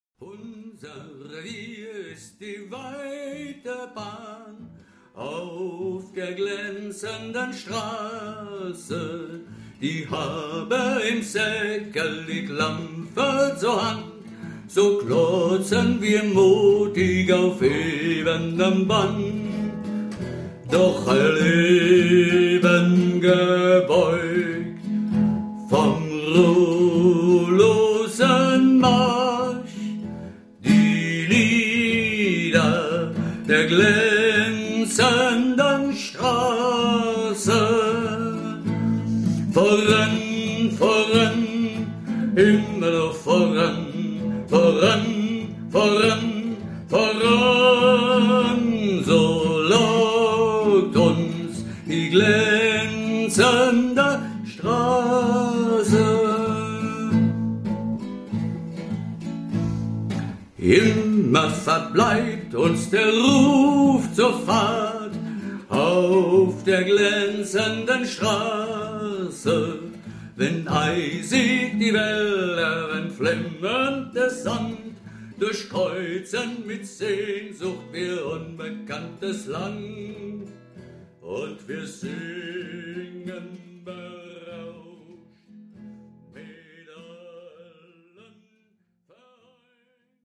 Werkstatt - Aufnahmen 21